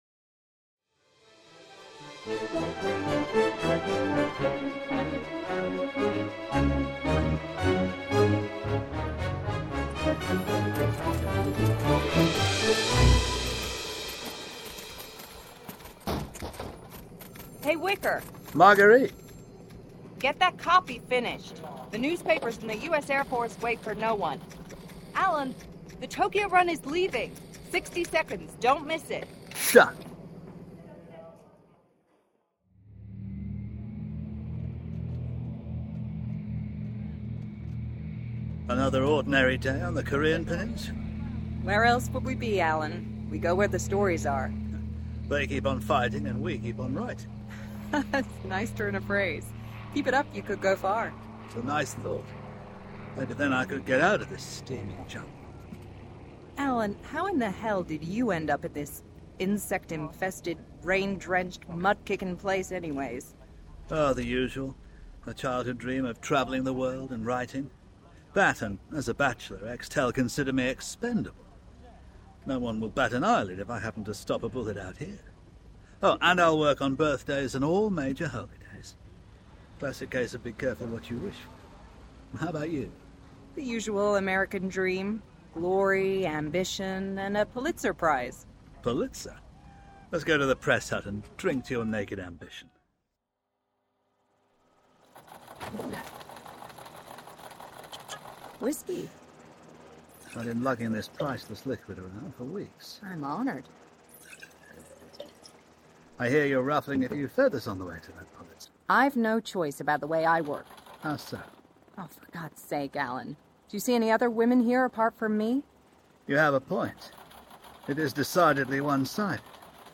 This is a thrilling 60 min audio drama based on real events, which takes the listeners behind the scenes of the launch of Whickers World and how the BBC nearly triggered WW3.
This play was recorded at Greenwich Studios, London.